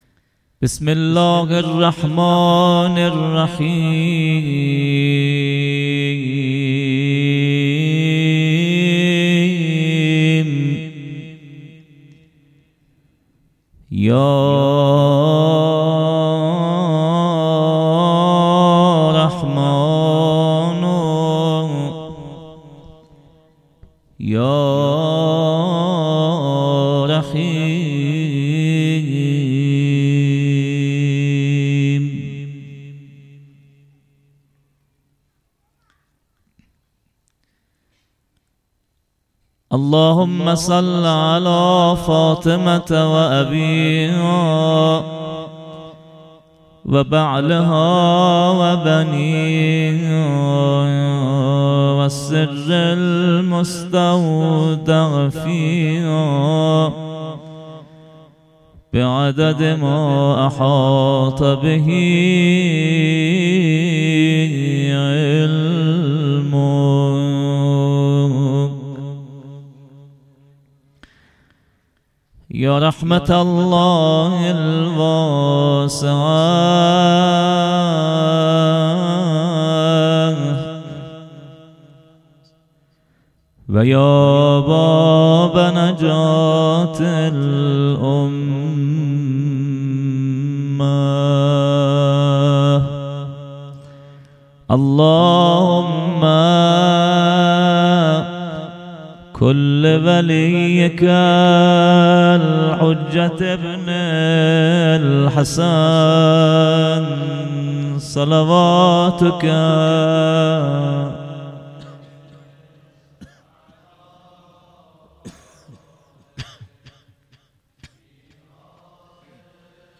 شب دوازدهم محرم 98 - مقدمه و روضه